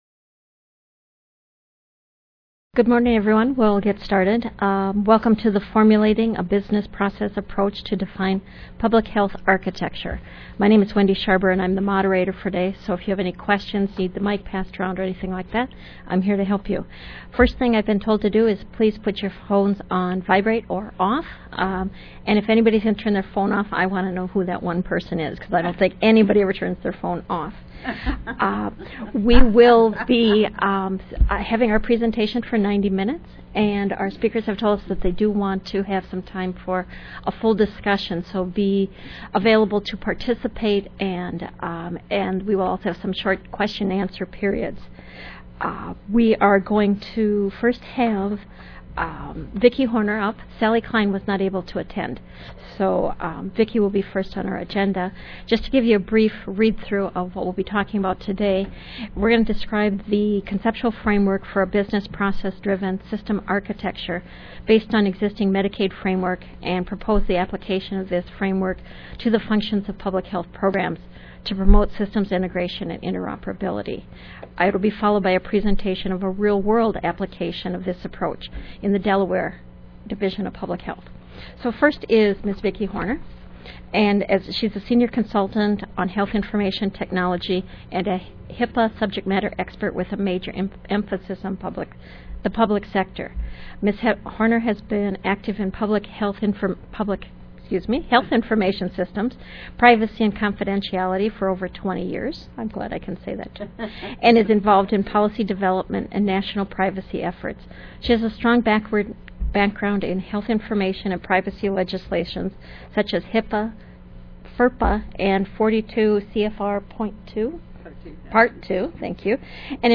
In order to receive the 25.5 Continuing Education Credits for this conference, please click Training and Continuing Education Online Monday, August 22, 2011: 10:30 AM-12:00 PM Dunwoody This session describes a conceptual framework for a business process driven system architecture based on an existing Medicaid framework and proposes the application of this conceptual framework to the functions of public health programs to promote system integration and interoperability. This will be followed by the presentation of a real world application of this approach in the Delaware Division of Public Health.